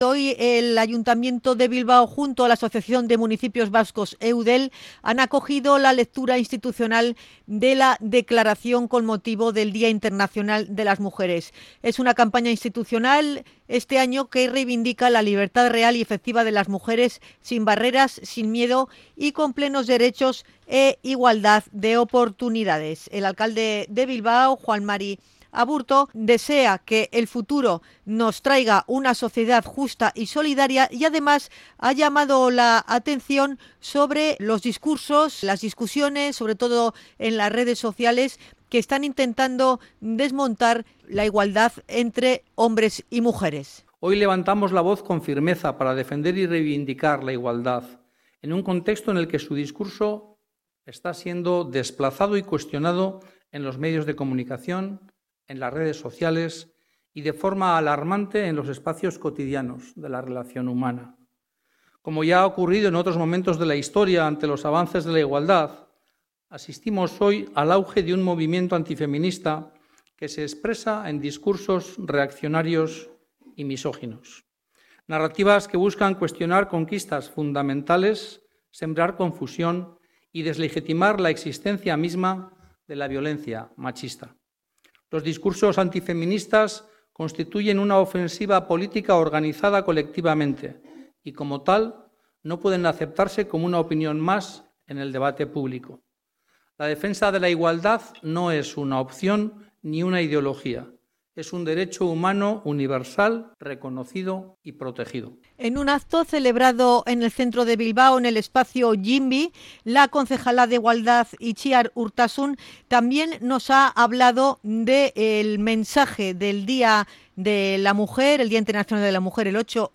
Hortzmuga Teatroa ha intervenido en la lectura de la Declaración Institucional / RADIO POPULAR/HERRI IRRATIA
REPORTAJE-8M-.mp3